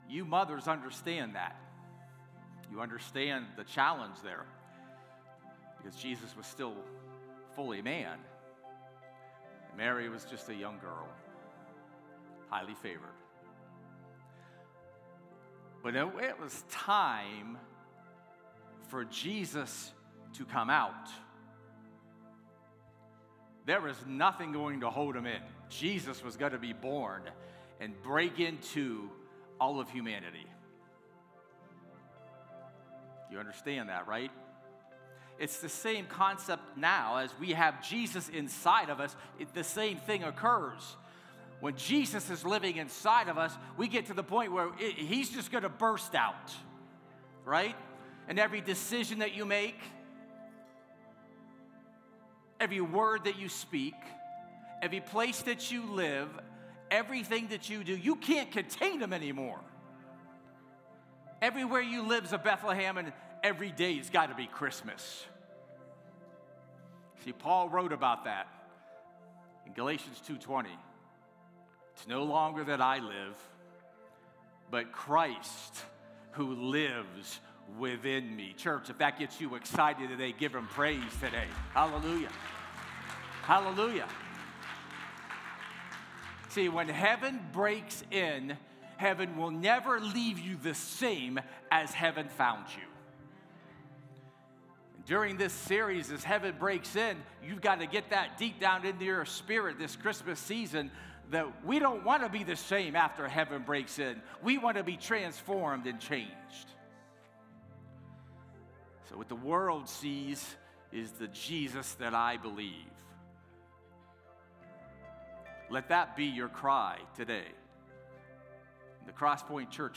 A message from the series "Heaven Breaks In!."